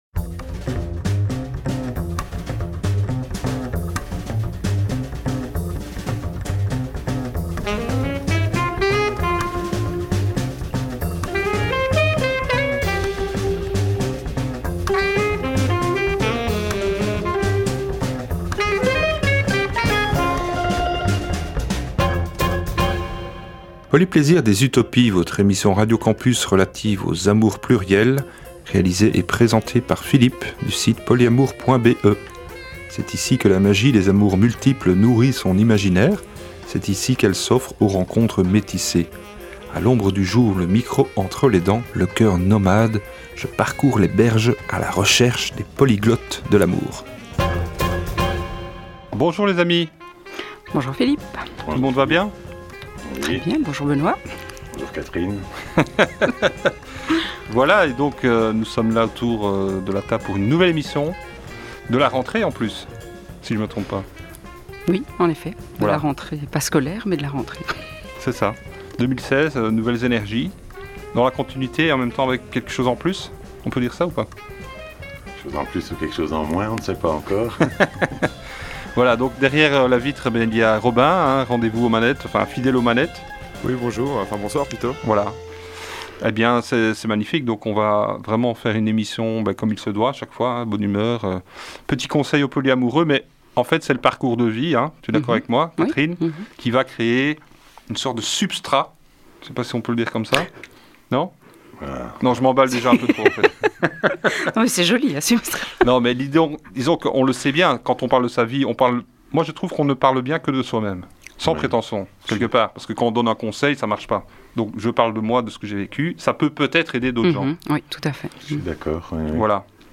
L’émission radio des amours plurielles